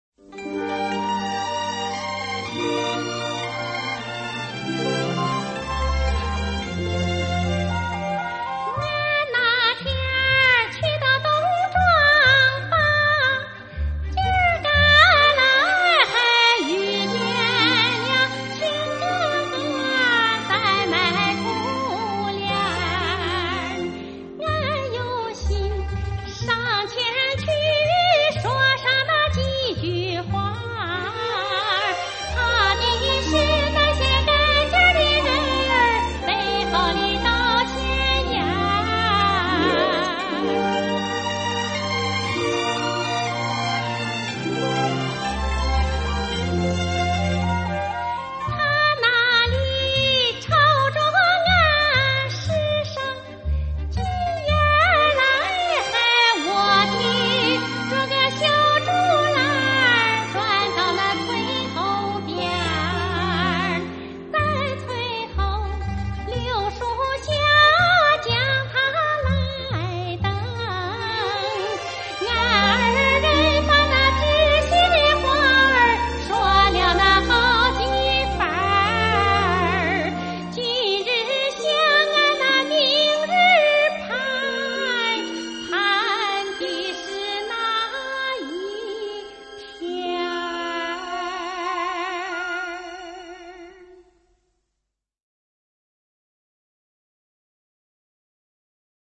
山东胶县民歌